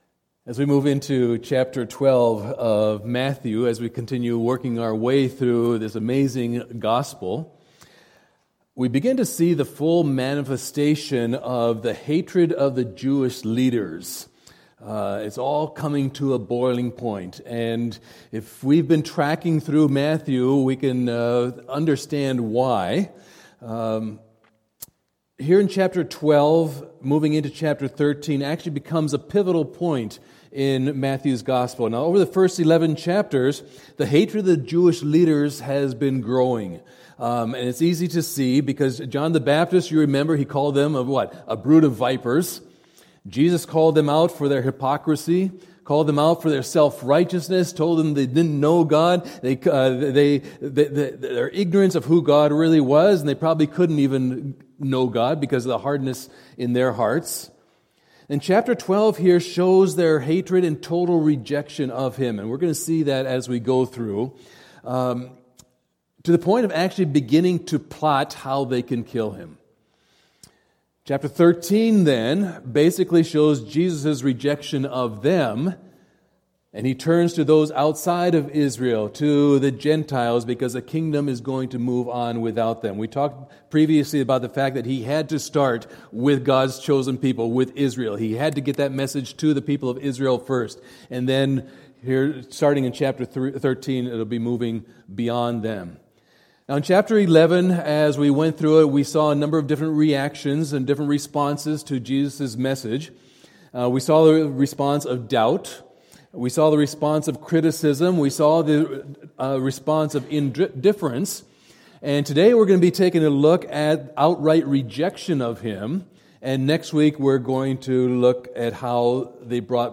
Lord of the Sabbath Preacher